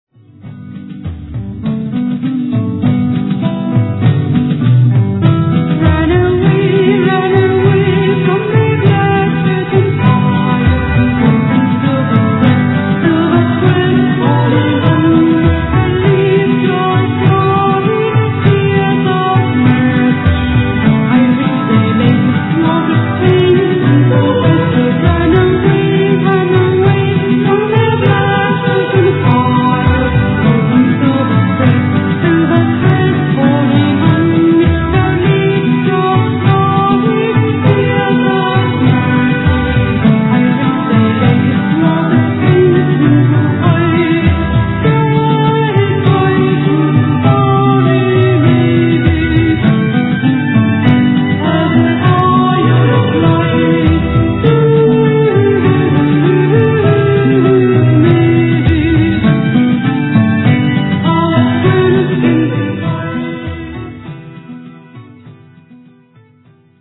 ゴシック・フォーク
voice, drum machine
classic, acoustic and synth guitars, drums machine
piano, keyboards